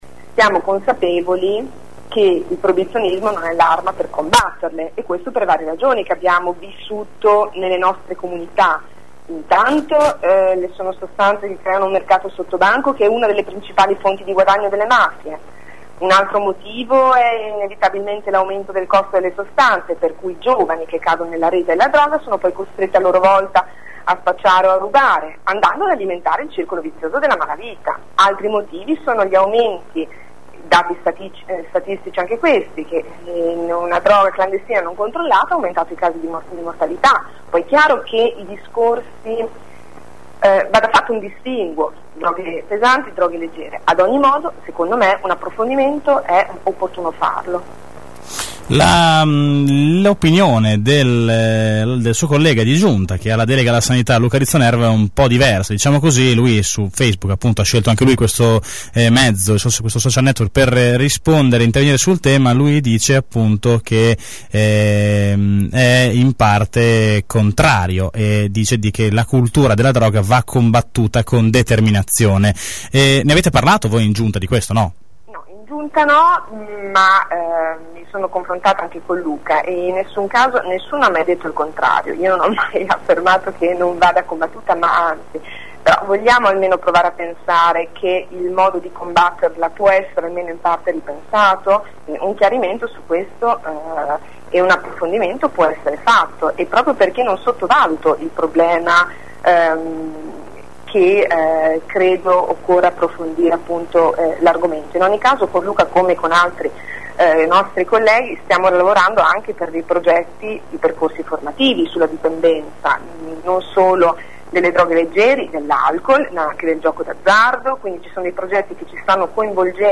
Intervistata ai nostri microfoni Monti conferma: “Il proibizionismo crea un mercato sottobanco che è uno delle principali fonti di guadagno delle mafie“.